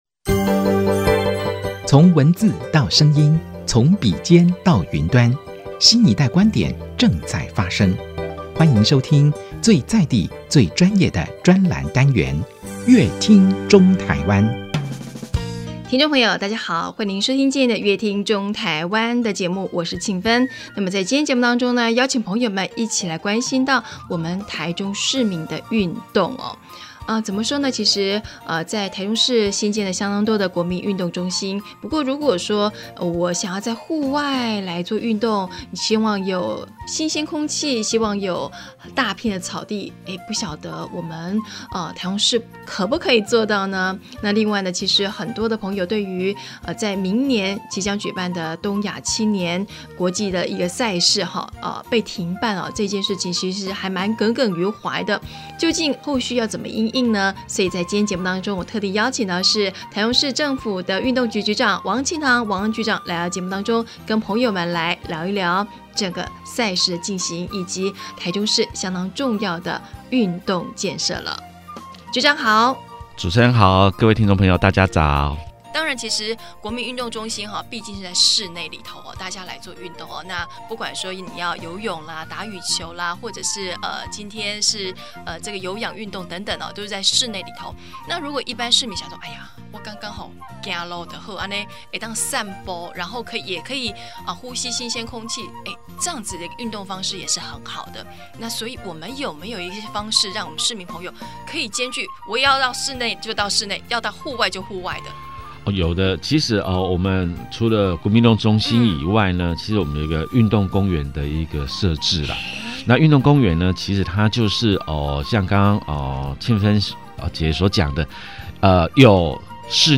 本集來賓：臺中市政府運動局王慶堂局長 本集主題：「台中市準備好了 舉辦國際性亞太青年運動會」 本集內容： 原計